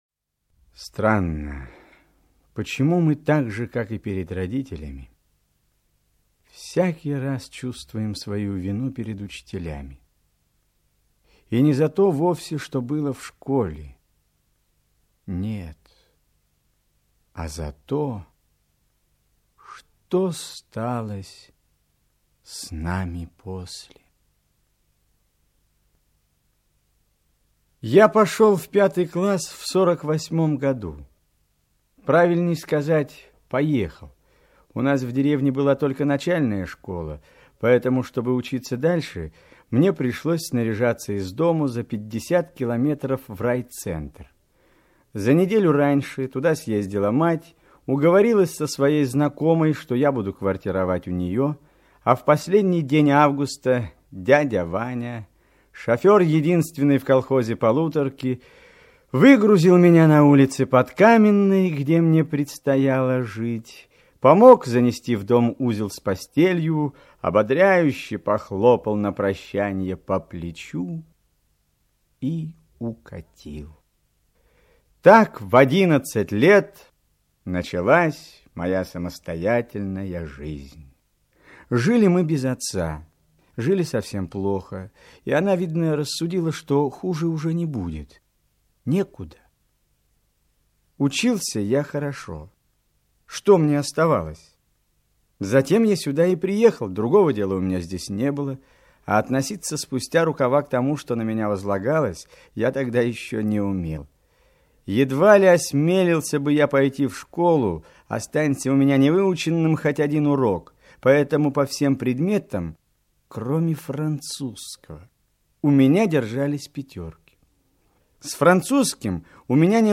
Уроки французского - аудио рассказ Распутина В.Г. Как молодая учительница помогла одинокому голодающему мальчику.